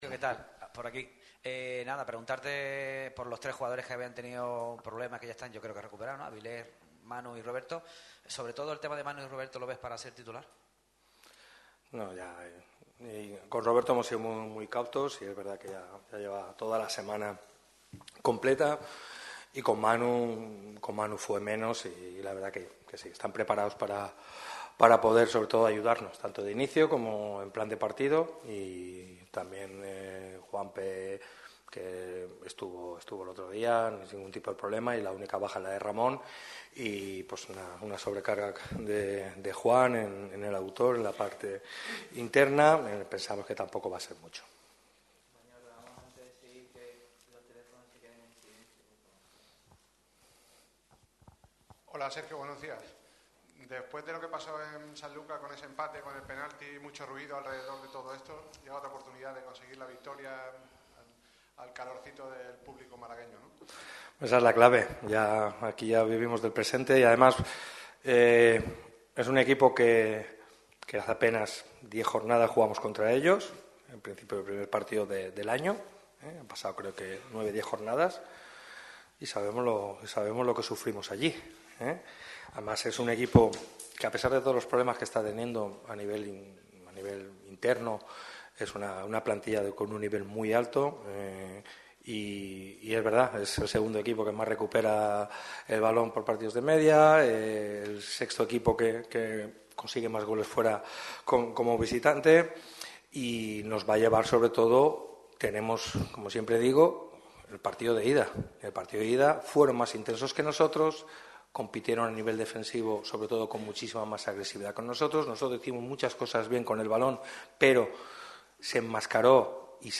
Sergio Pellicer ha comparecido en la sala de prensa ‘Juan Cortés’ del estadio de La Rosaleda. El motivo es la previa de la jornada 28 frente al Intercity el próximo domingo en el templo malaguista.